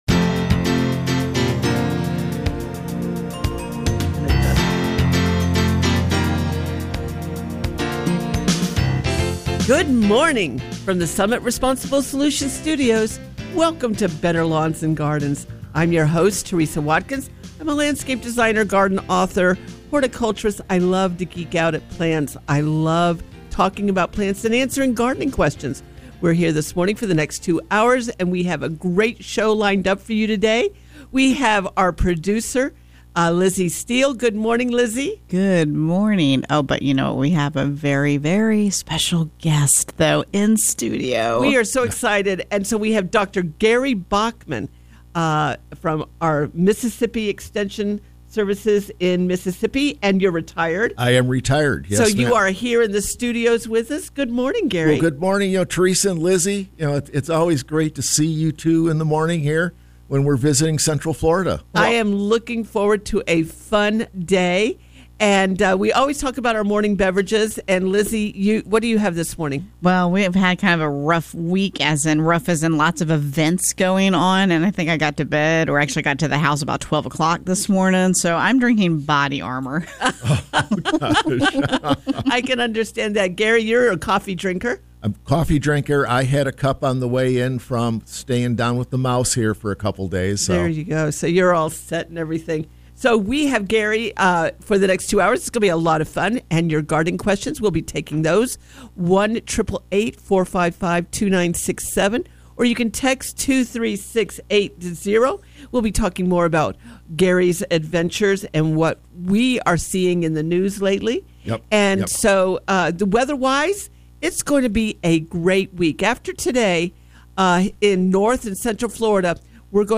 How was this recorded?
Better Lawns and Gardens Hour 1 – Coming to you from the Summit Responsible Solutions Studios.